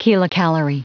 Prononciation du mot kilocalorie en anglais (fichier audio)
Prononciation du mot : kilocalorie